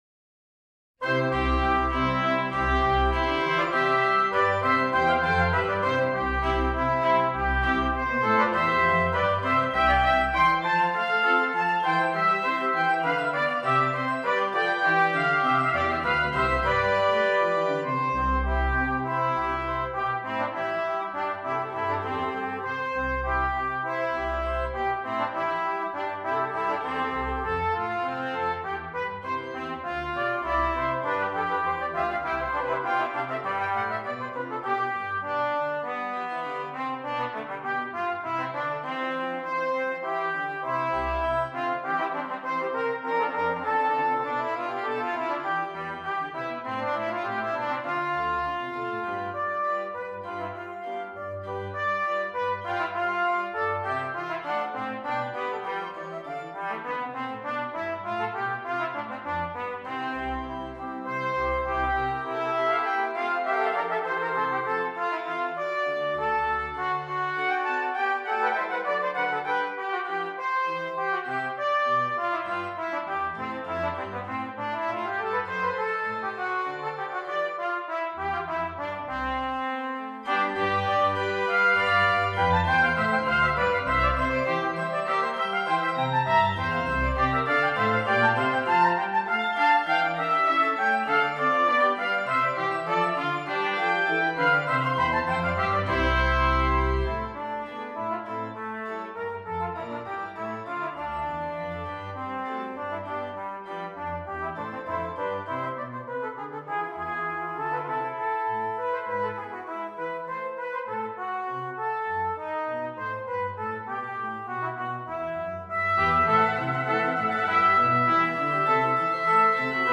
3 Trumpets and Keyboard